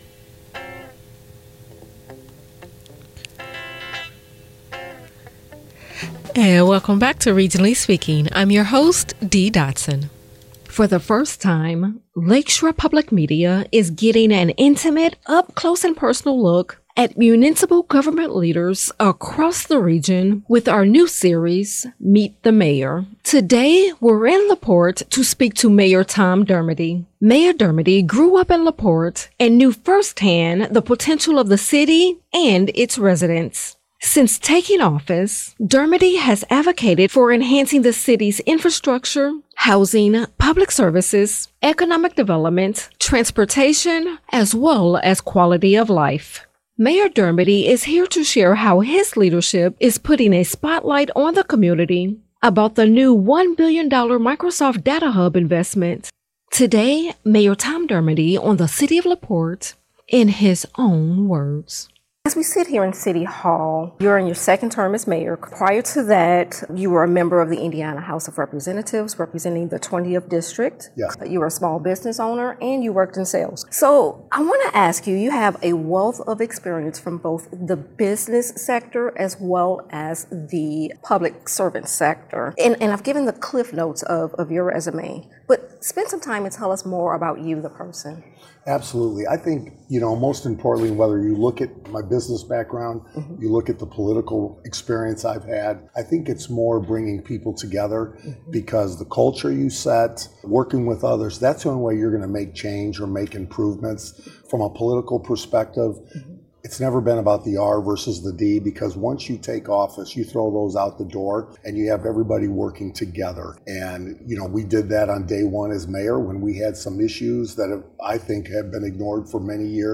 Addressing the most important local issues facing the Region during a daily hour of stimulating conversation with local news-makers.